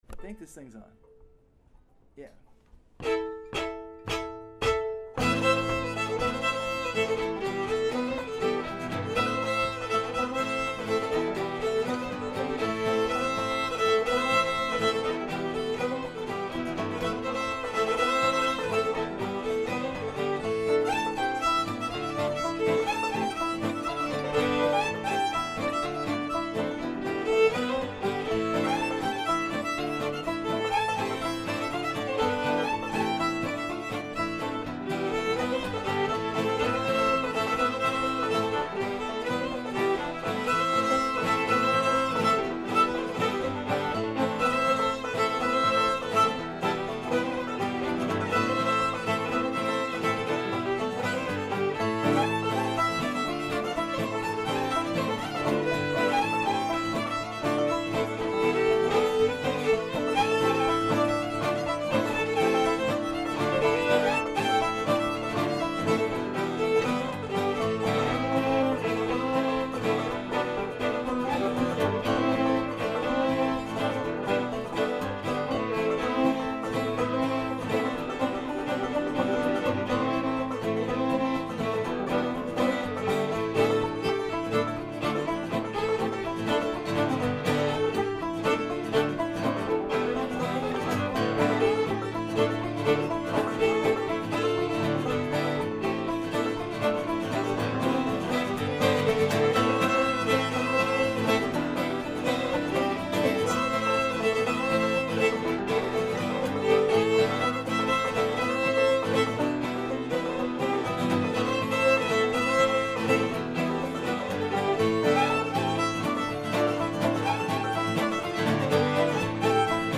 Sand Island is possibly the first fiddle tune I ever wrote.
Contratopia has been playing this tune for a number of years and I almost never play guitar on it, but the guitar was in my hands that afternoon.